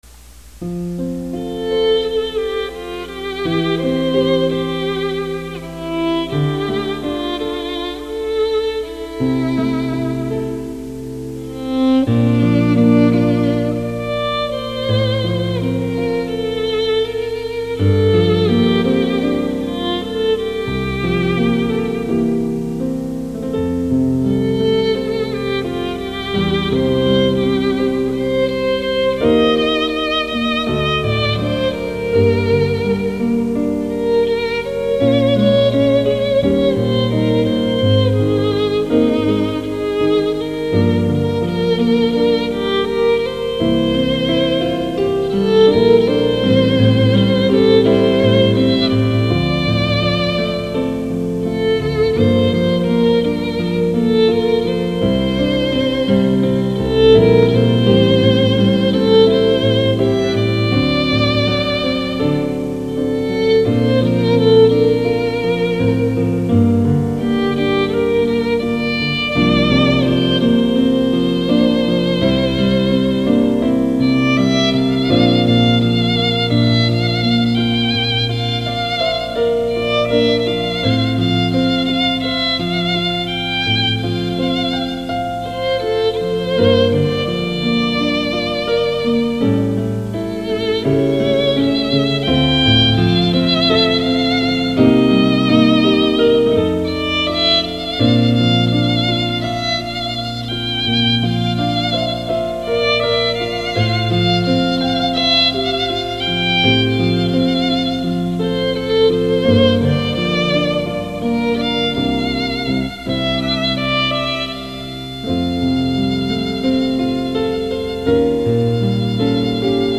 Michiru's sad violin song